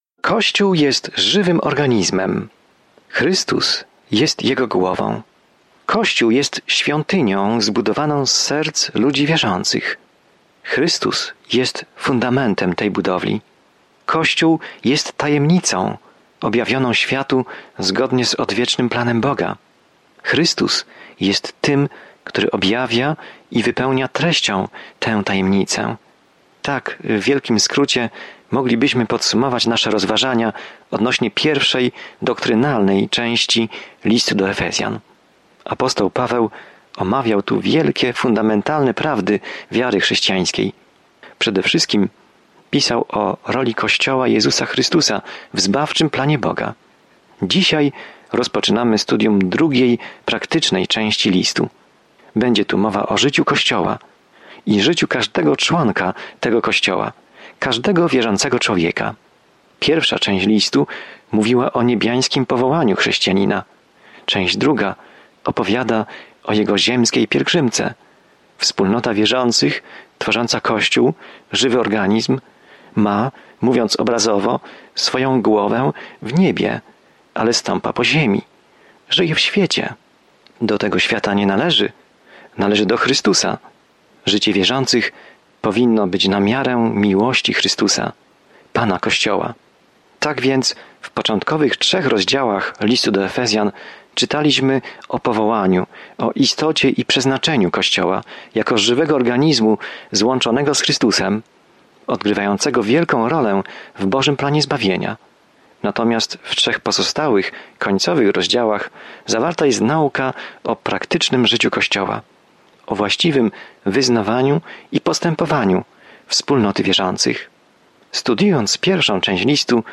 Pismo Święte Efezjan 4:1-2 Dzień 13 Rozpocznij ten plan Dzień 15 O tym planie List do Efezjan wyjaśnia, jak żyć w Bożej łasce, pokoju i miłości, ukazując piękne wyżyny tego, czego Bóg pragnie dla swoich dzieci. Codziennie podróżuj przez Efezjan, słuchając studium audio i czytając wybrane wersety słowa Bożego.